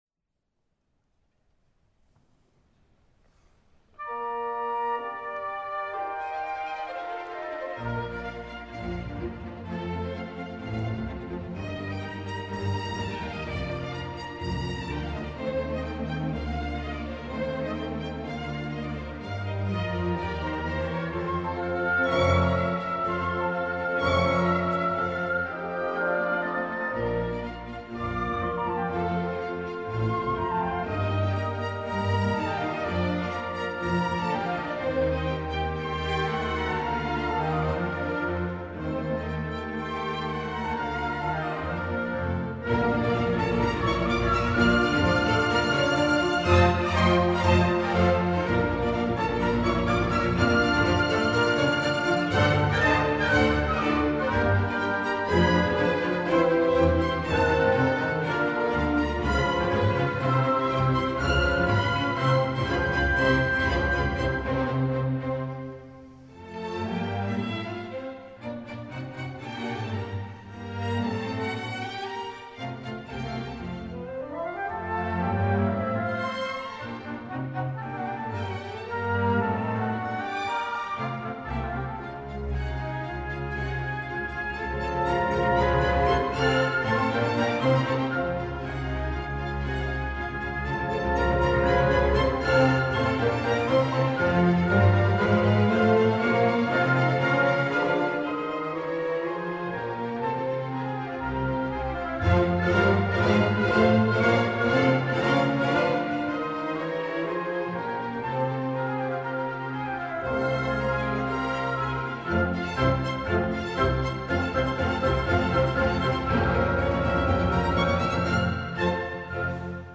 F. Schubert: Sinfonía nº 5 en Si bemol Mayor, D. 485 | Basque National Orchestra - Euskadiko Orchestra
F. Schubert: Sinfonía nº 5 en Si bemol Mayor, D. 485
Saison Symphonique